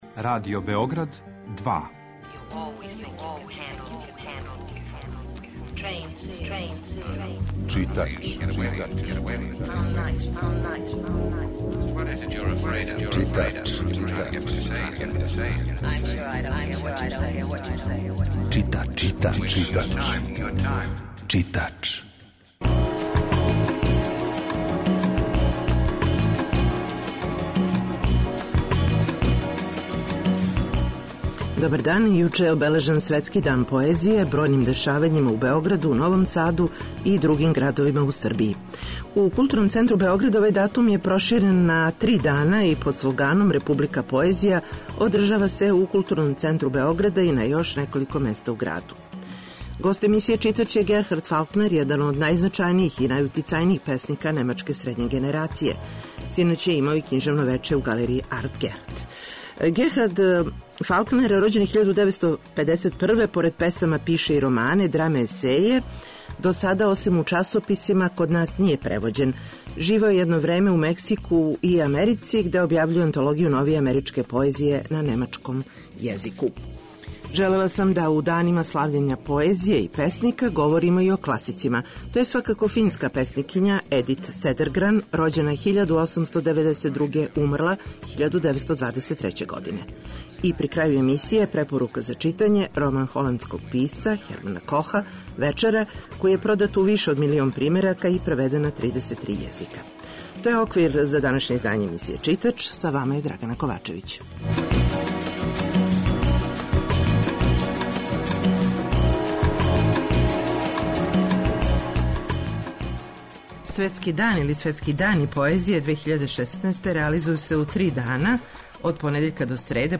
Фалкнер je и гост емисије Читач .
Емисија је колажног типа